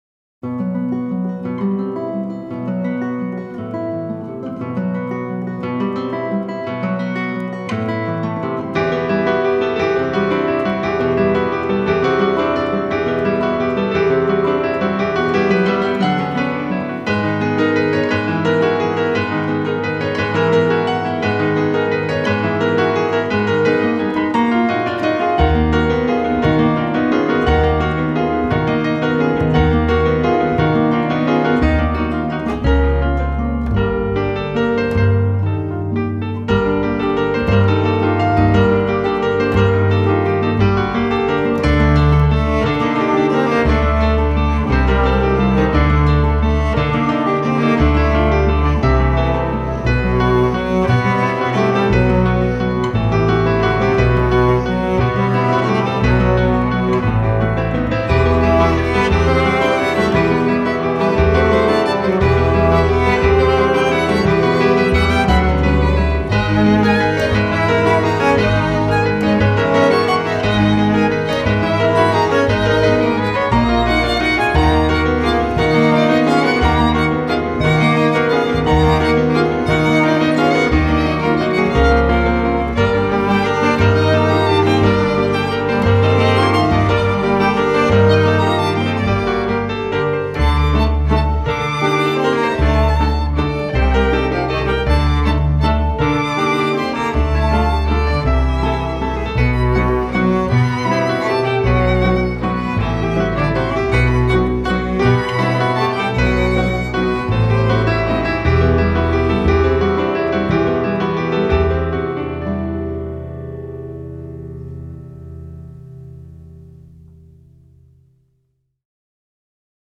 Keine Worte. Nur Töne.